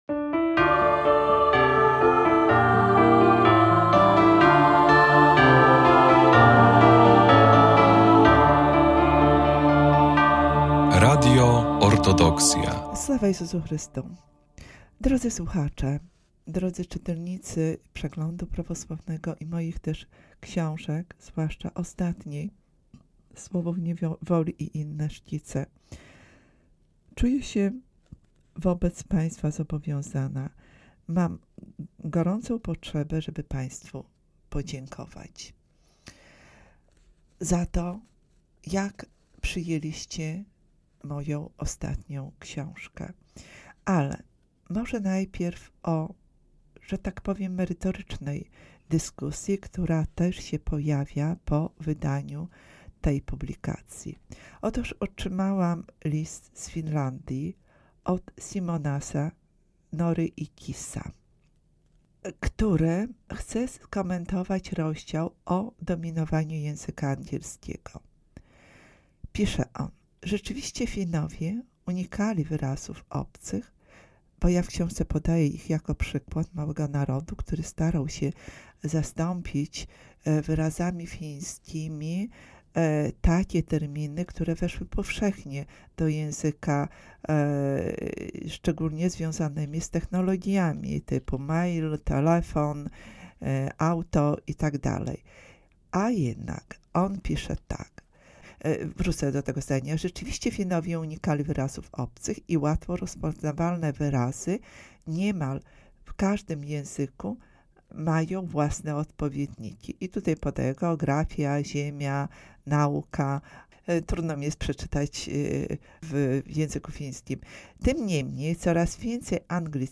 Prowadząca prezentuje i omawia wybrane lektury warte uwagi, często związane z kulturą, historią i duchowością, dzieląc się refleksjami i czytelniczymi rekomendacjami.